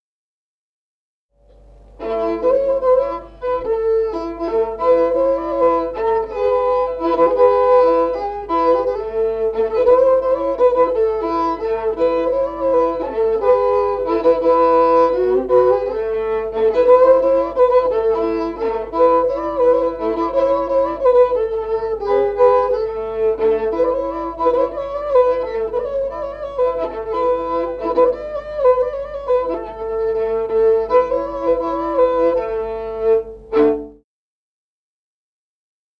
Музыкальный фольклор села Мишковка «На Бога гляньте», свадебная, репертуар скрипача.